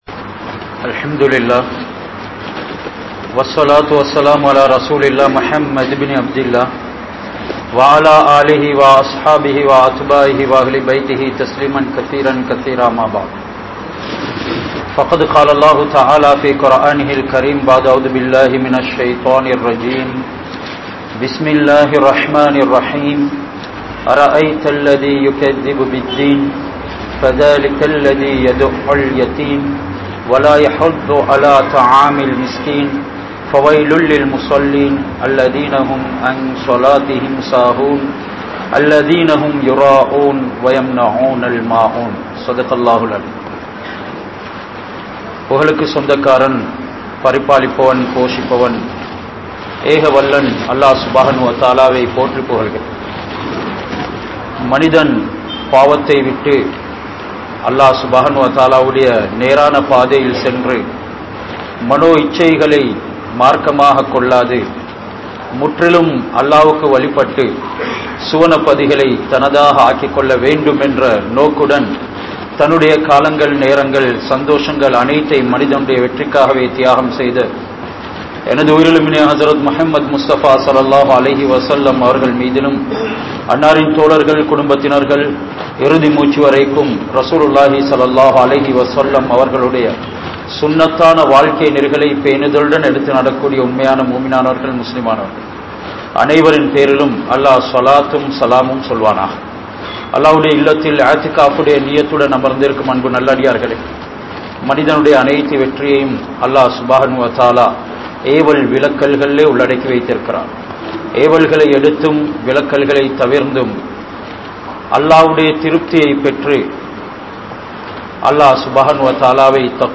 Surah Al Maauoon (சூரா அல் மாஊன்) | Audio Bayans | All Ceylon Muslim Youth Community | Addalaichenai
Kandy, King Street Jumua Masjidh